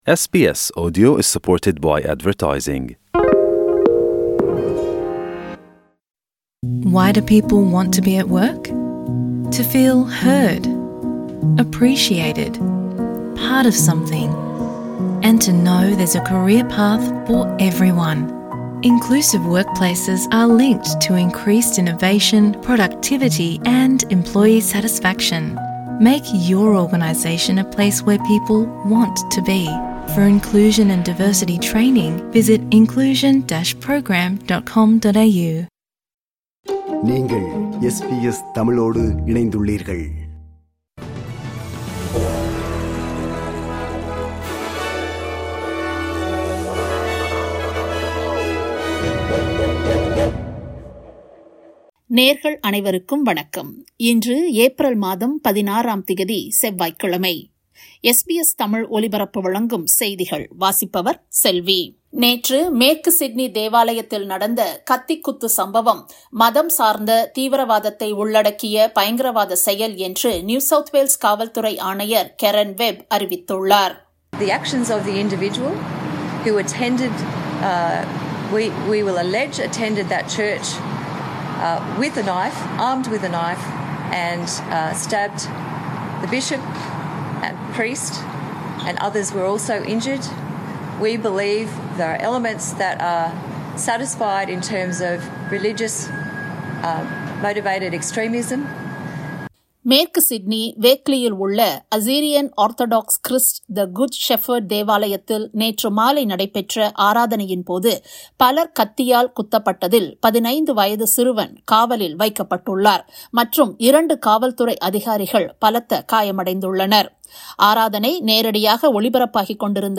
SBS தமிழ் ஒலிபரப்பின் இன்றைய (செவ்வாய்க்கிழமை 16/04/2024) செய்திகள்.